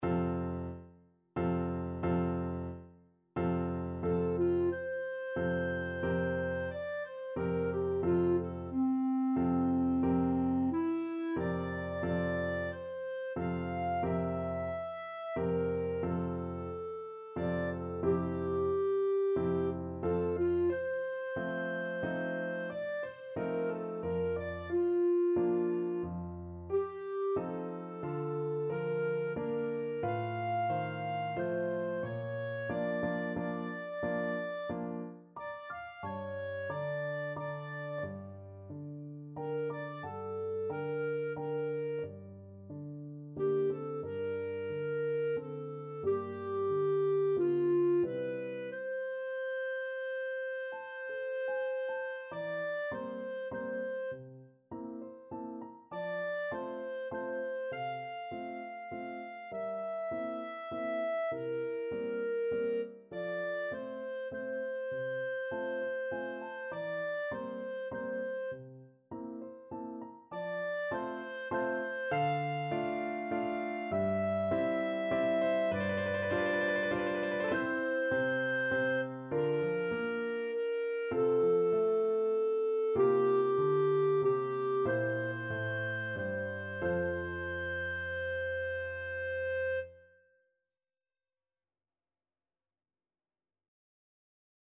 Classical Strauss II,Johann Bruderlein und Schwesterlein from Die Fledermaus Clarinet version
3/4 (View more 3/4 Music)
F major (Sounding Pitch) G major (Clarinet in Bb) (View more F major Music for Clarinet )
~ = 90 Allegretto moderato
Clarinet  (View more Easy Clarinet Music)
Classical (View more Classical Clarinet Music)
strauss_fledermaus_bruderlein_CL.mp3